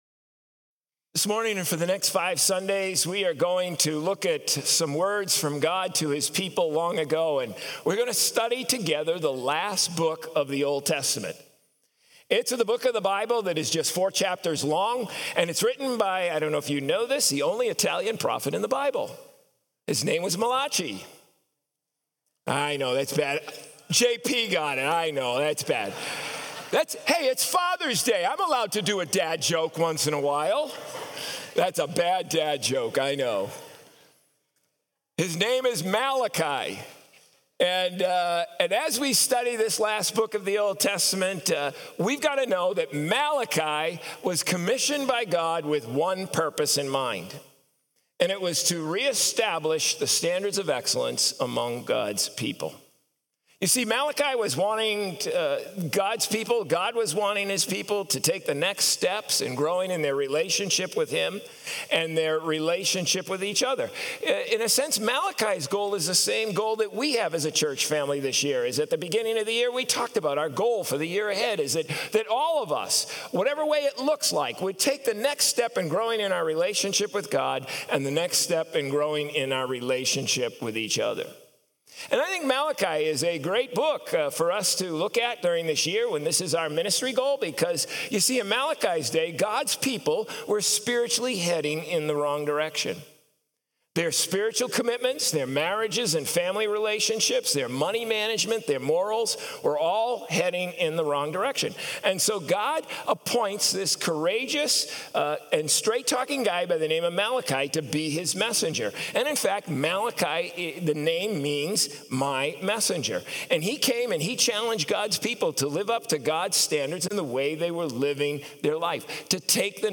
Sermons | COMMUNITY Covenant Church